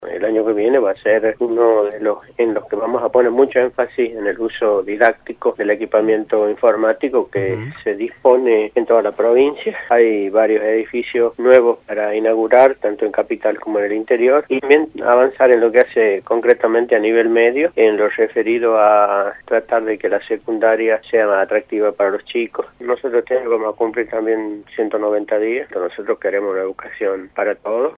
Walter Flores, ministro de Educación, por Radio La Red